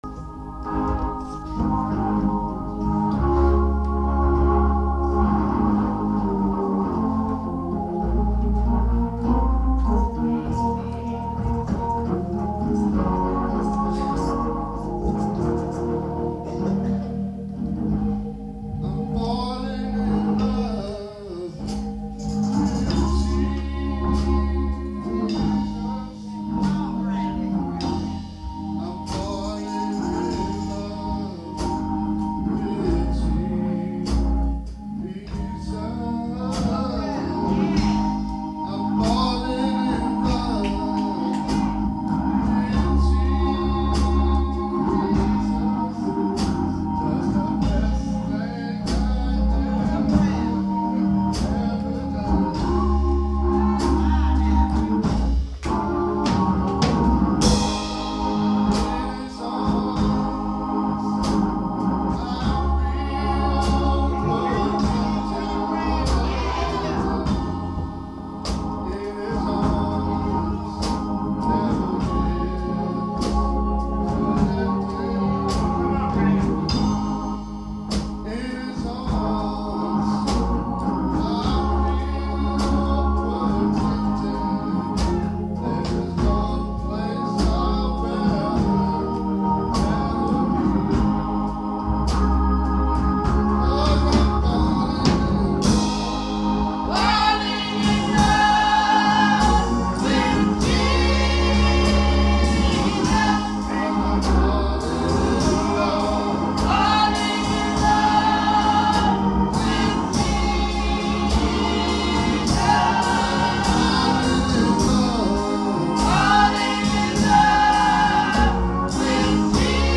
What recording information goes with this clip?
June 29th 2014 Sunday Morning.